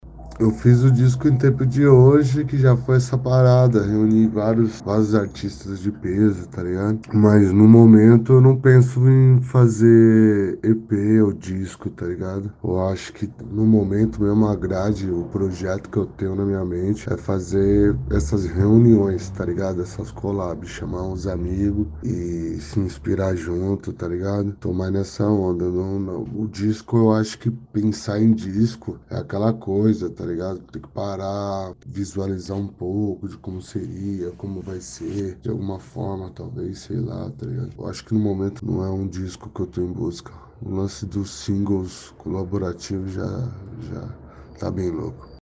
Black Pipe Entrevista